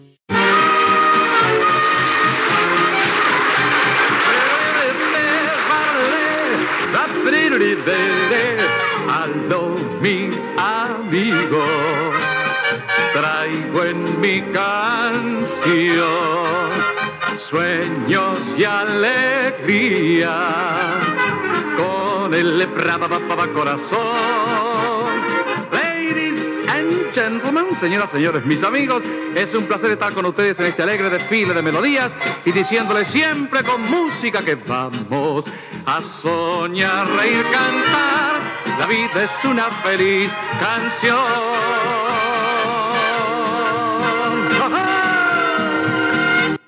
Sintonia cantada del programa